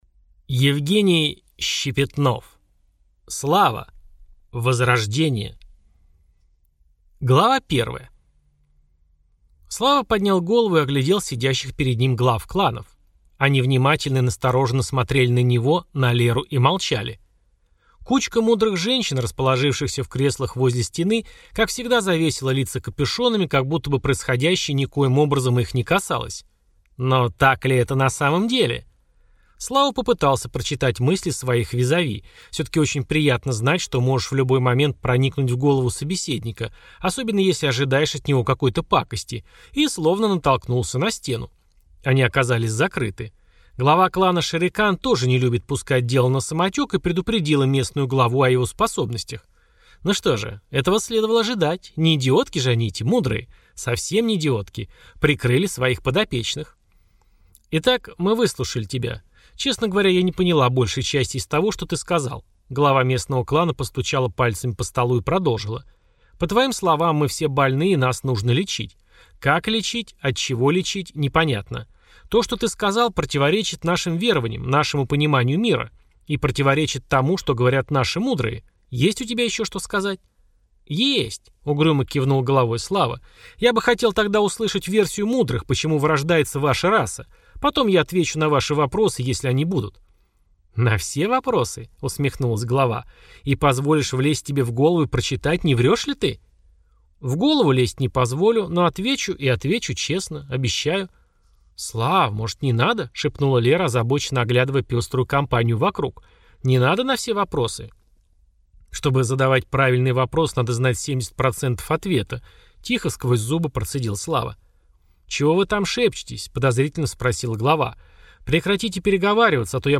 Аудиокнига Слава. Возрождение | Библиотека аудиокниг